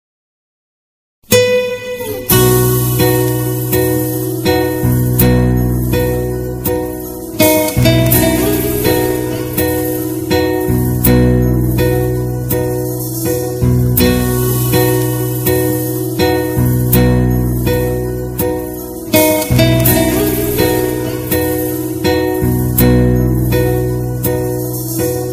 Guitar song
guitar solo